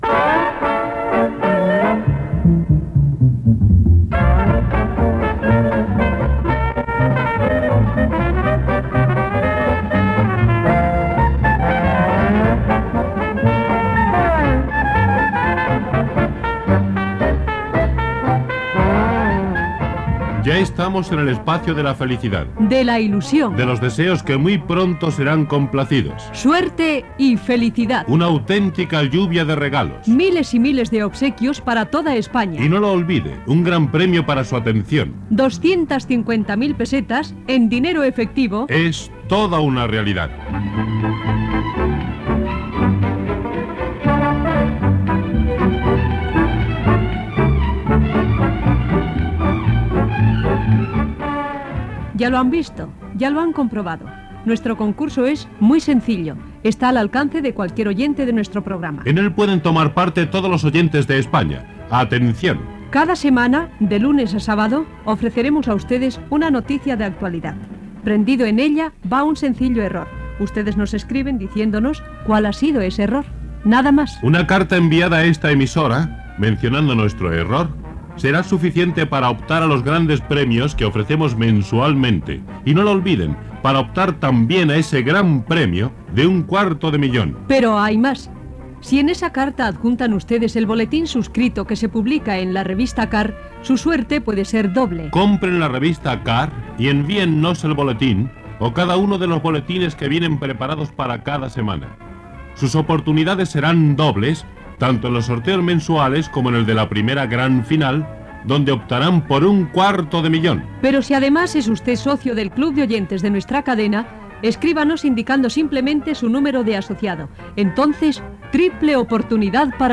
Secció "Suerte y felicidad". Presentació del concurs, publicitat, pregunta sobre el futbolista Luis Suárez, contingut de la Revista CAR, publicitat, comiat amb identificació de la cadena
Entreteniment